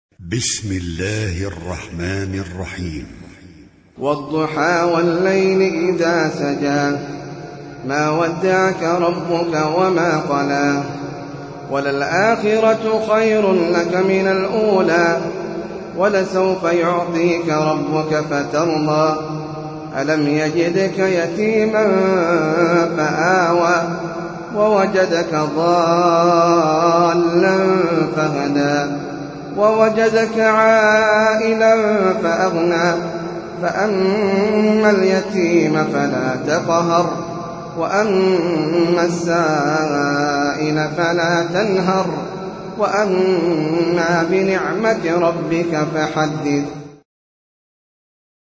سورة الضحى - المصحف المرتل (برواية حفص عن عاصم)
جودة عالية